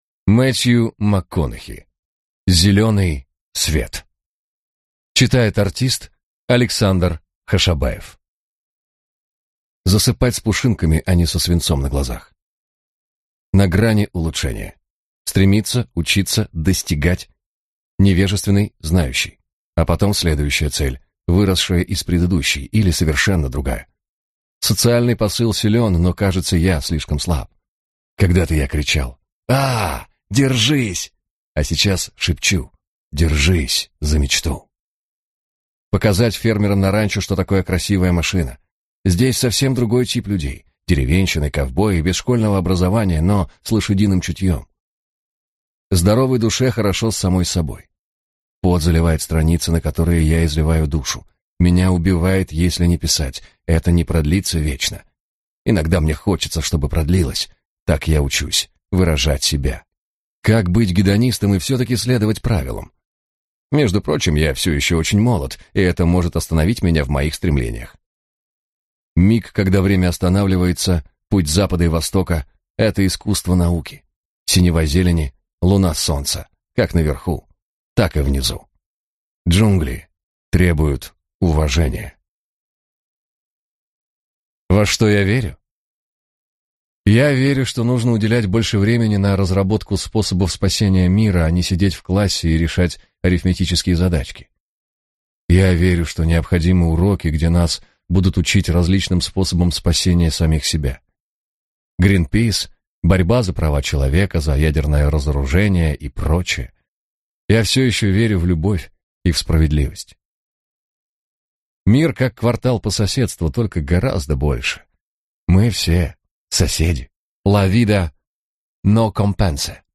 Аудиокнига Зеленый свет | Библиотека аудиокниг